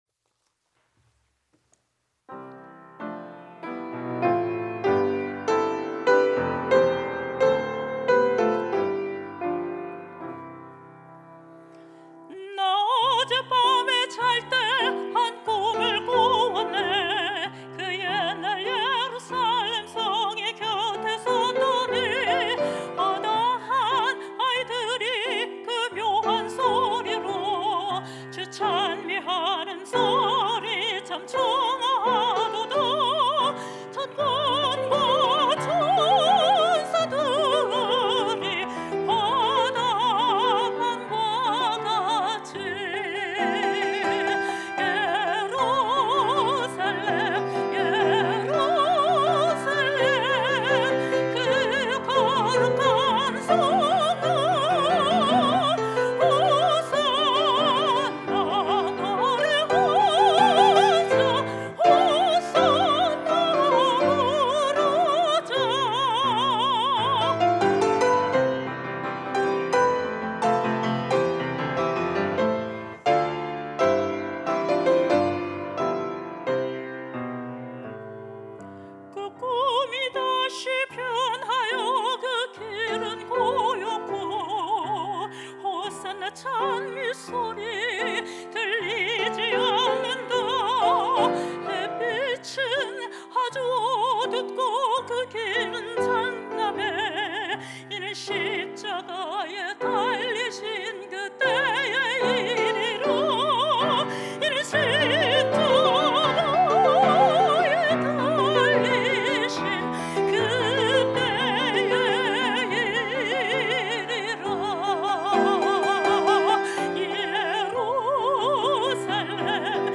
특송과 특주 - 거룩한 성